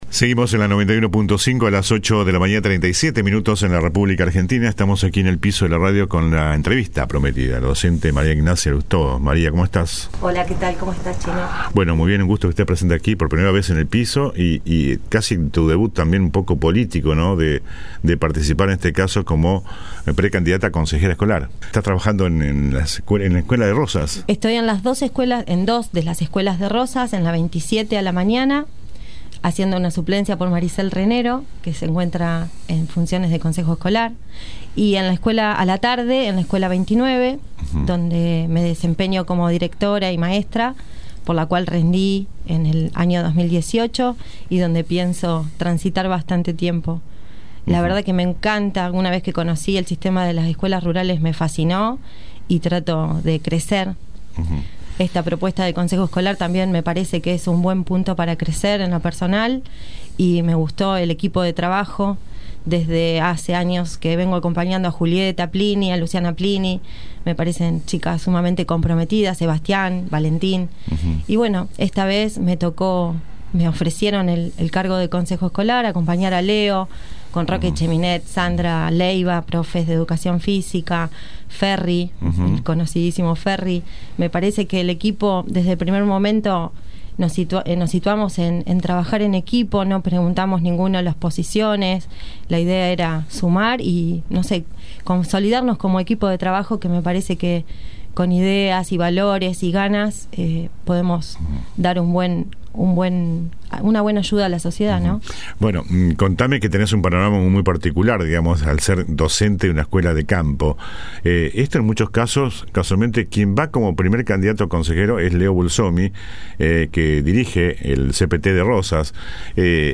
Esta mañana visitó “El Periodístico” y se refirió a los tiempos electorales que se avecinan.
ENTREVISTA